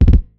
notification_sounds
kick roll.mp3